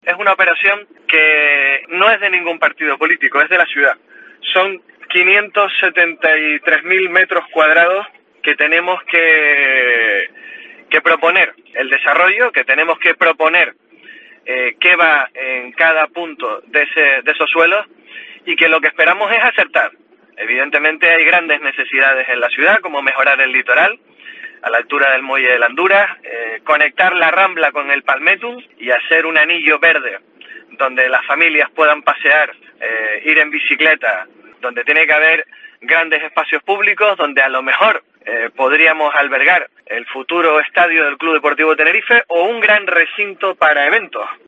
Carlos Tarife, portavoz del PP en Santa Cruz